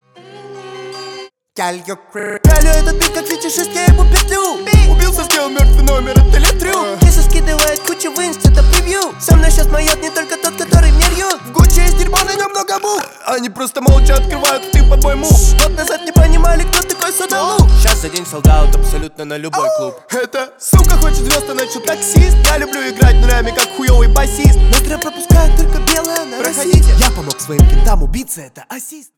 клубные